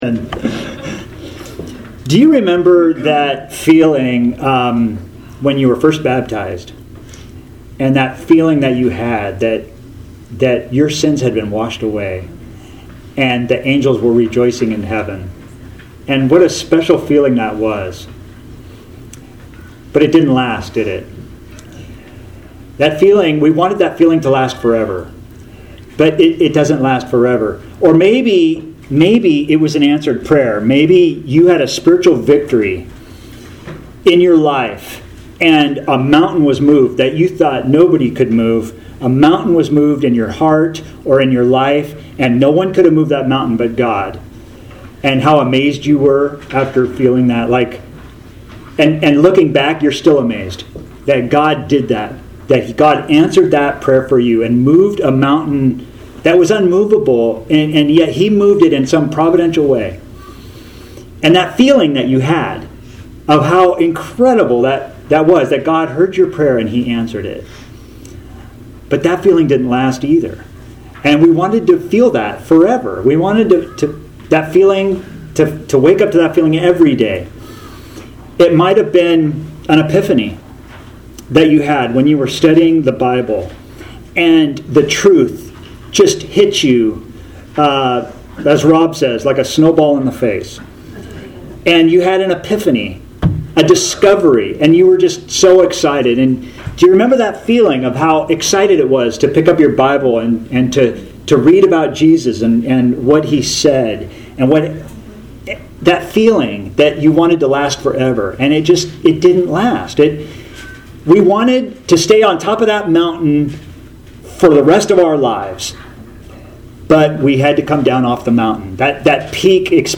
Author jstchurchofchrist Posted on October 4, 2023 Categories Sermons Tags Jesus , Luke - Gospel For All